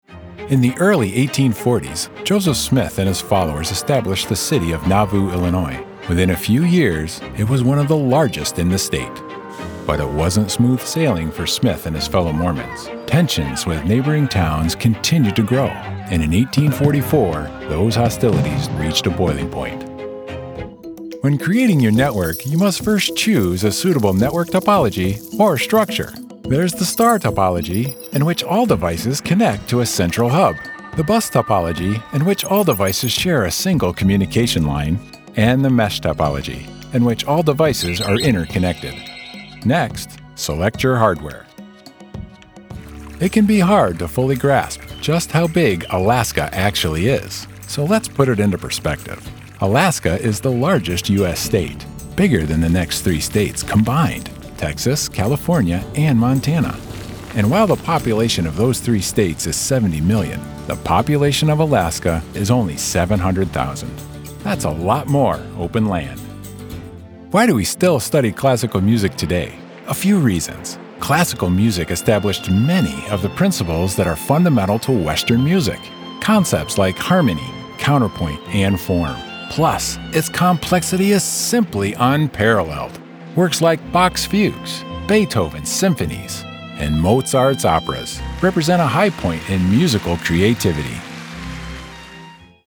Demos
Middle Aged